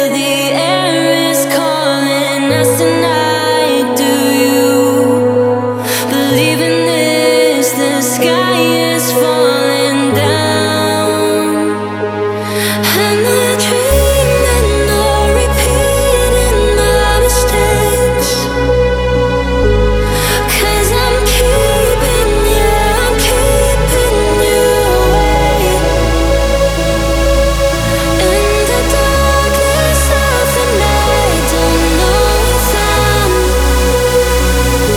Жанр: Танцевальные / Хаус
House, Dance